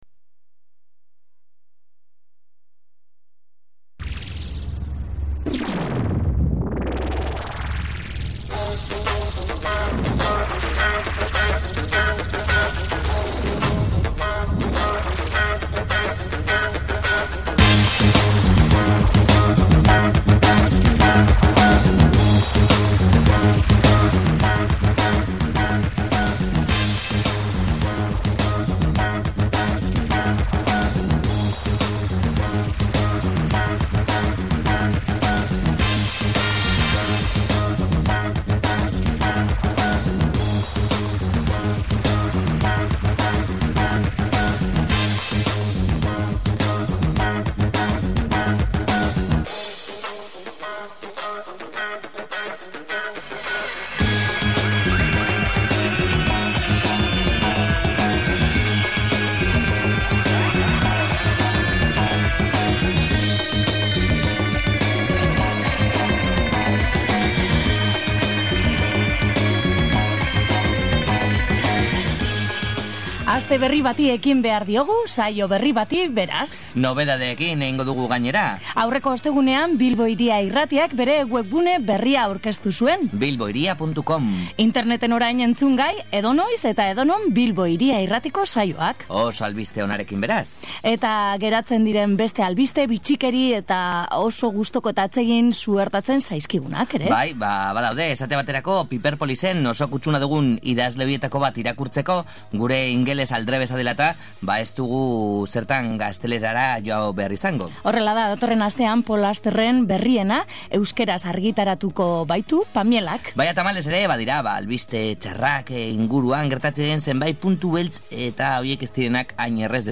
Lehenbiziko elkarrizketa PAM (Puto Amoak Matematiketan) taldeko kideei egin diete, beren hirugarren diskoa aurkeztu berria dutela eta.
Azkenik, gogora dezagun John Trollope irratinobelaren 14. atala entzun daitekeela saio honetan, Ur azpian bizi izenburuarekin.